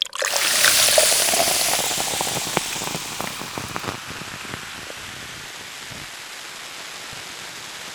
acid.wav